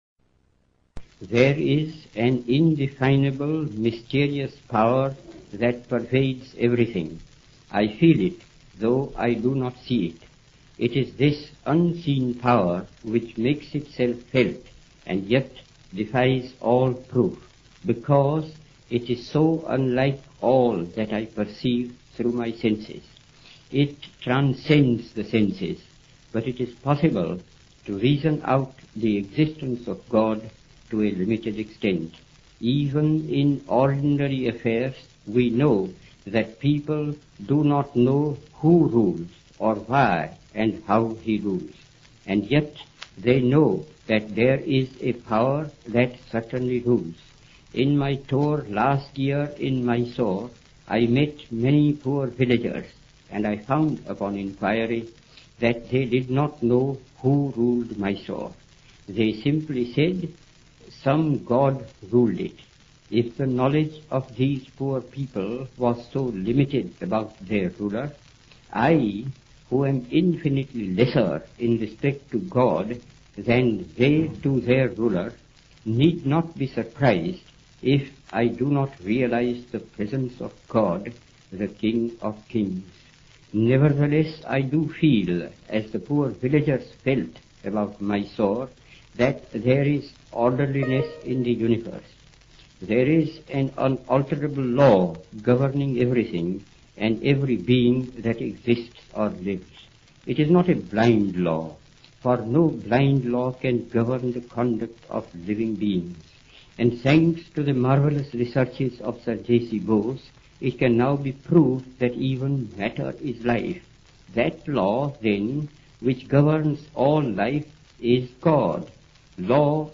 Mahatma_Gandhi_Speech_on God_London_1931.mp3